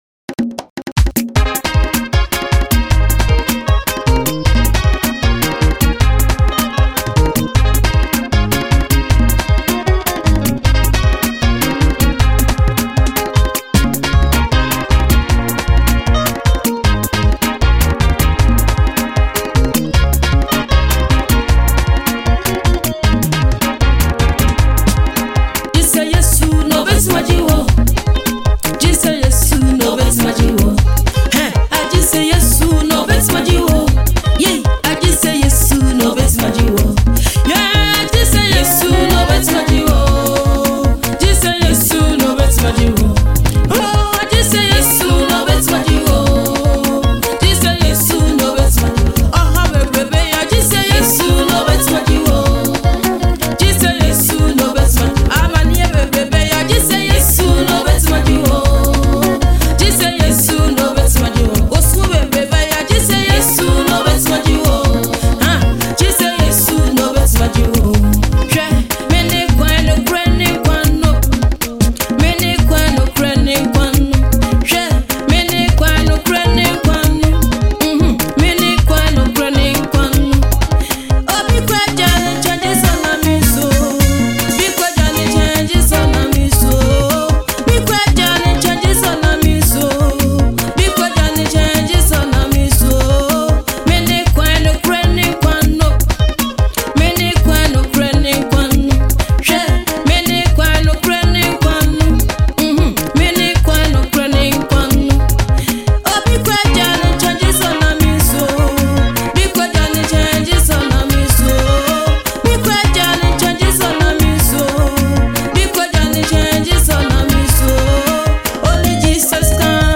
Ghanaian Gospel
This soulful track
With its catchy melody and heartfelt lyrics